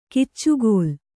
♪ kiccugōl